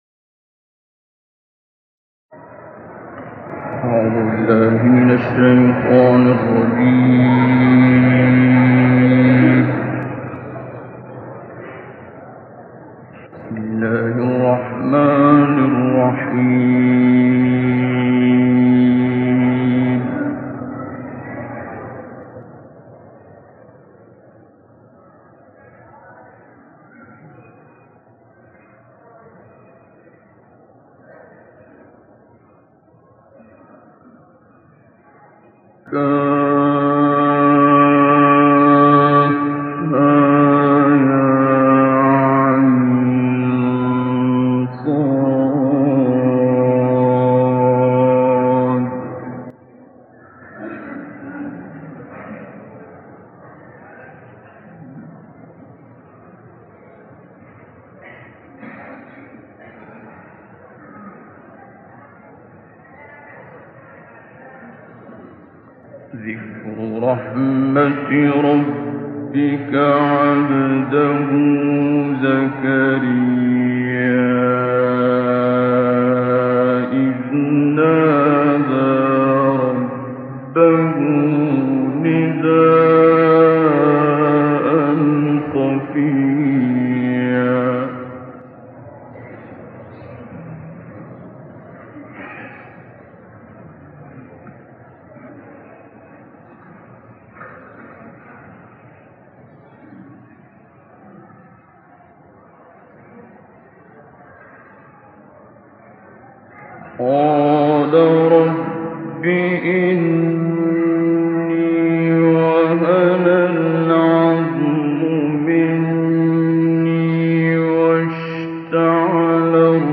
القارئ عبد الباسط محمد عبد الصمد - سورة مريم